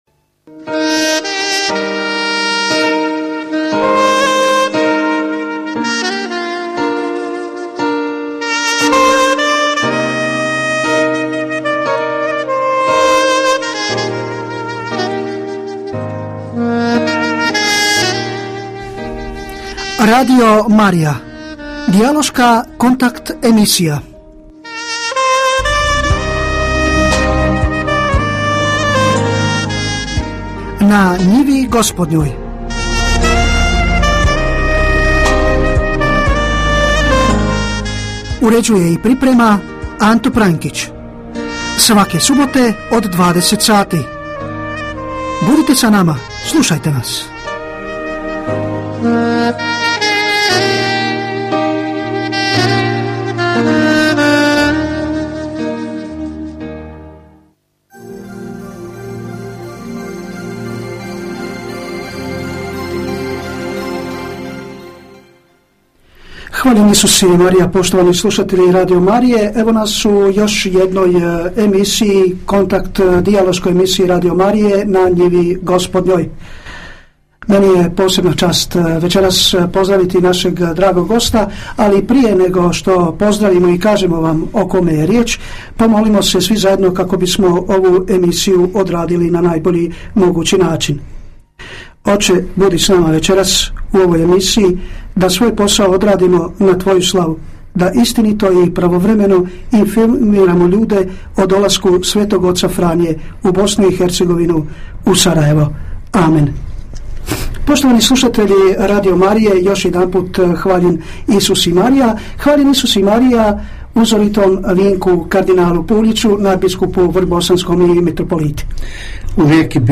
AUDIO: Kardinal Vinko Puljić gostovao na Radio Mariji